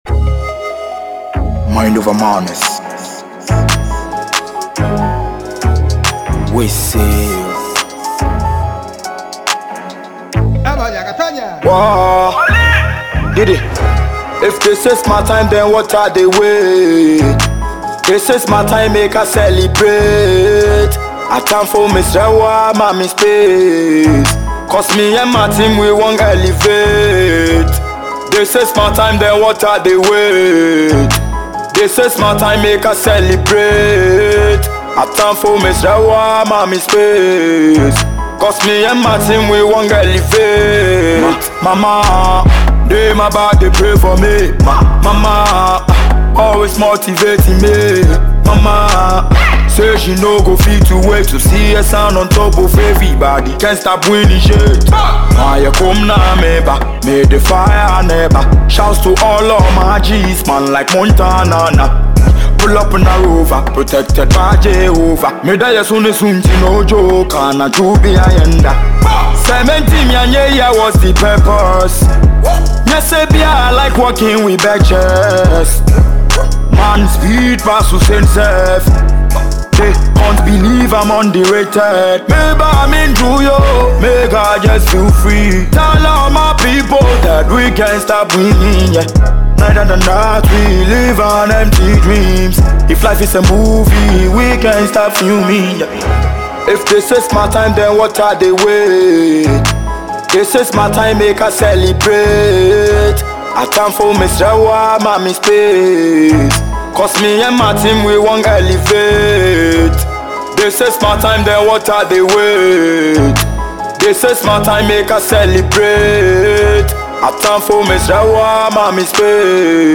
The upcoming Talented Ghanaian rapper